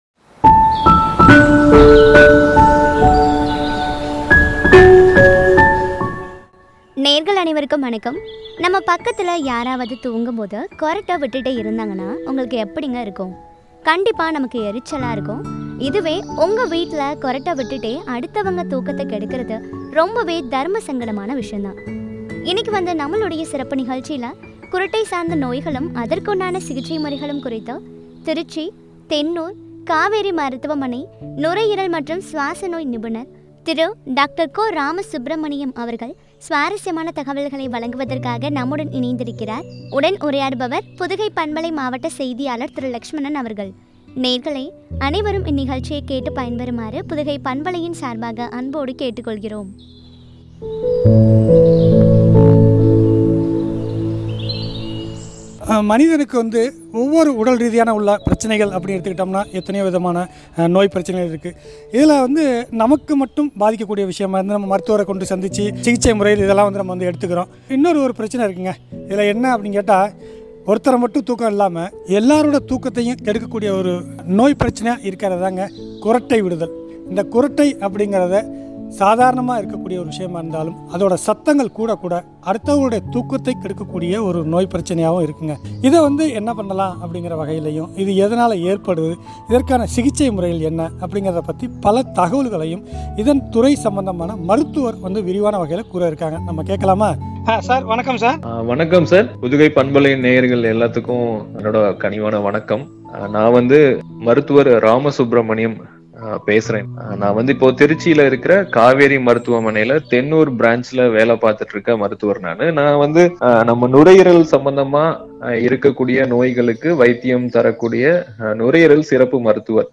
குறட்டை சார்ந்த நோய்களும், சிகிச்சை முறைகளும் பற்றிய உரையாடல்.